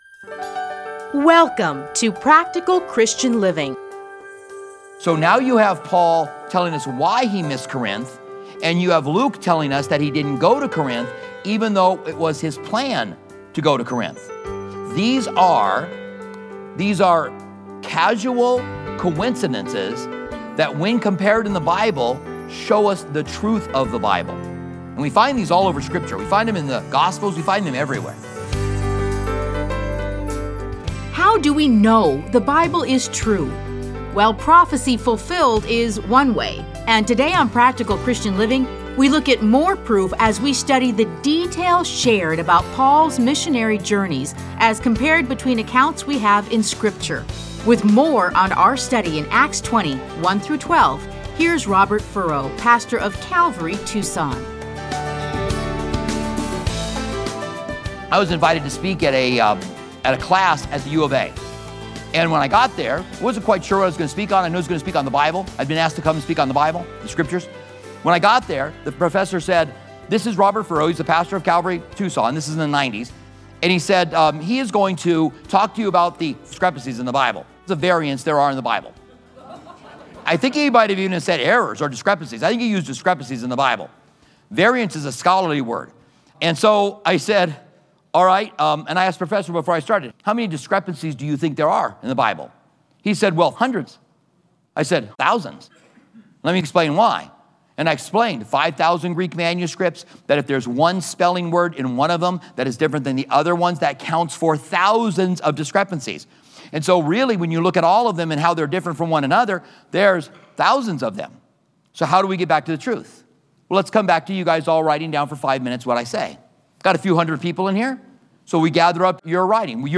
Listen to a teaching from Acts 20:1-12.